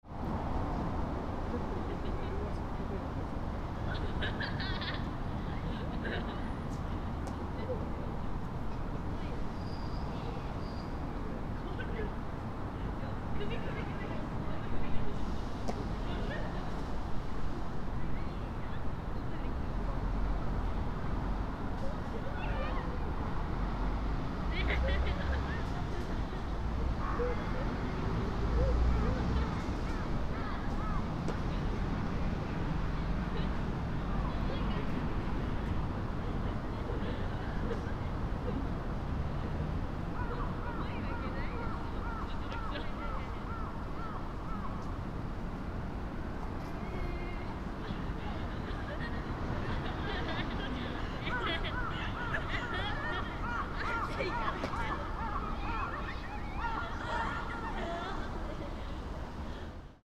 In Shinhama Park, a pair of a child and his father, a group of high school girls, and a group of boys were playing: a group of boys were playing with their ball at the back of the ground where they were permitted to use the ball.
During this recording, some crows were cawing, but no other birds were twittering.